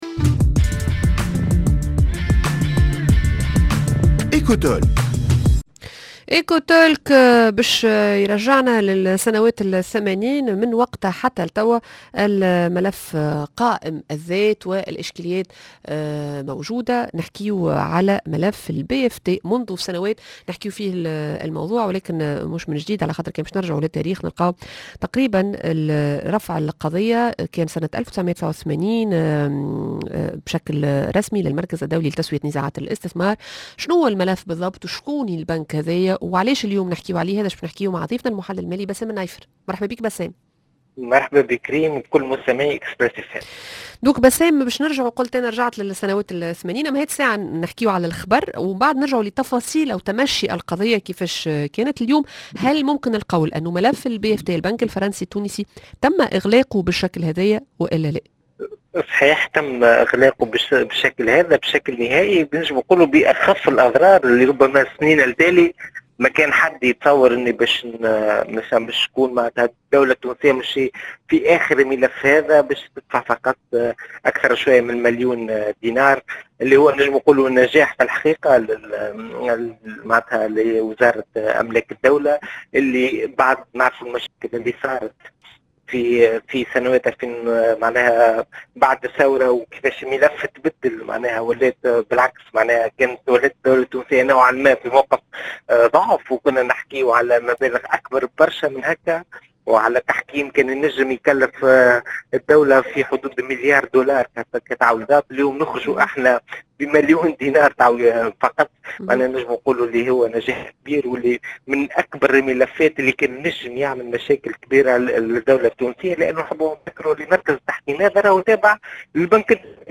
كل التفاصيل مع المحلل المالي